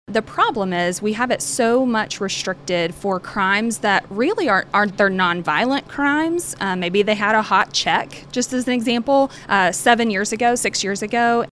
CLICK HERE to listen to commentary from Chancellor from State Senator Jessica Garvin.